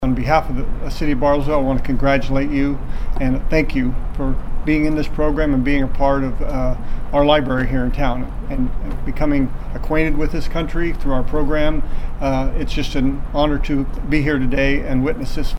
The mayor says the library's program to help immigrants receive their U.S. citizenship is one that shows great results.